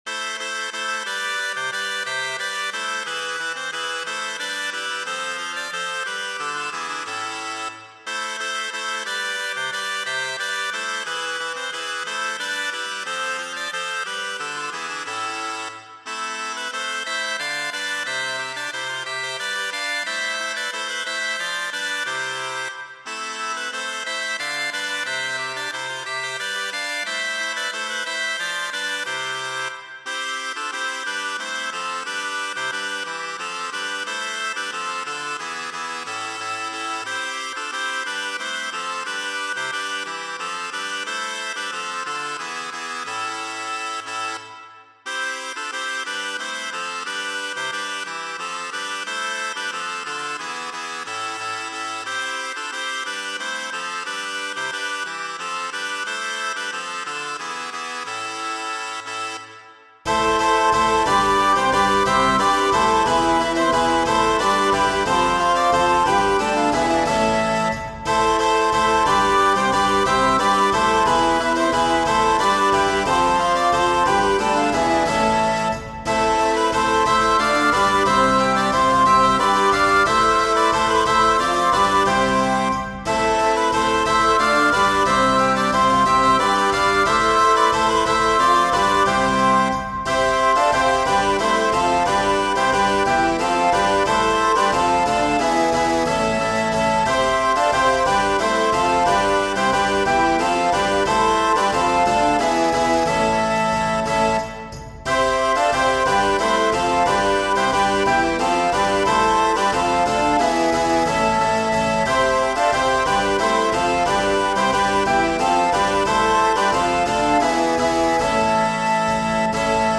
For my 200th work i decided to make my bread and butter, Galliards! This is for Crumhorn consort, Recorder consort, Rackett, Bassoon, Percussion and Harpsichord.